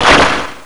se_water.wav